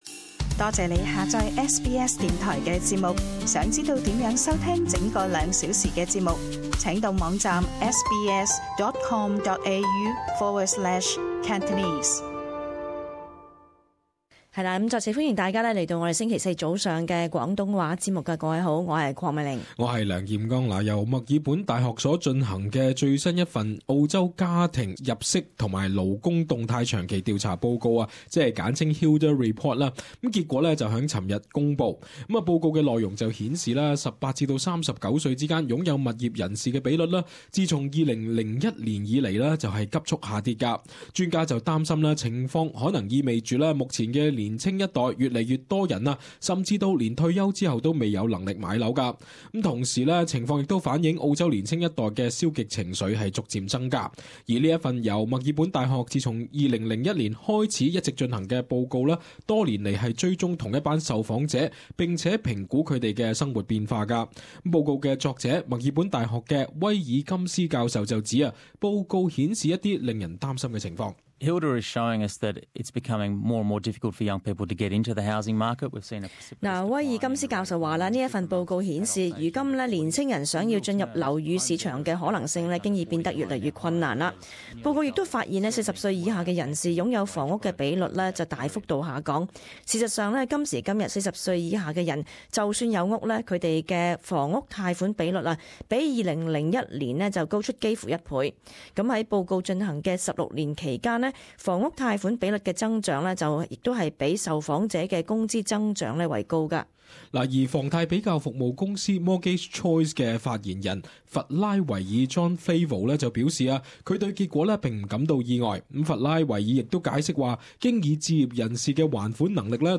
【時事報導】報告：澳洲年青一代上車希望渺茫